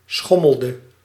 Ääntäminen
IPA : /ˈswʌŋ/